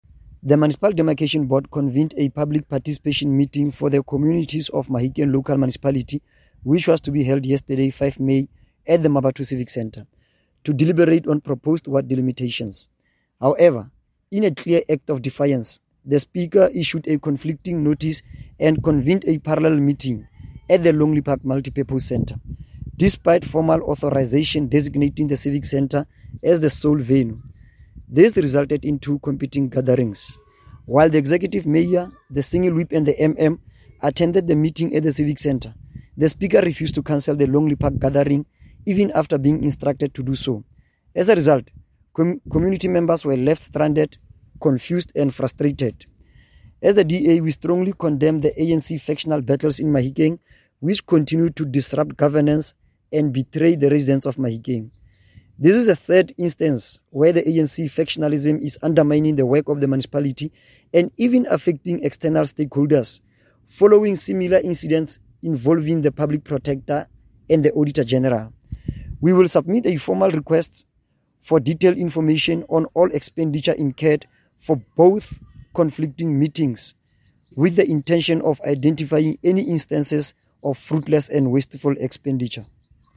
Note to Broadcasters: Please find attached soundbite in
English by Cllr Neo Mabote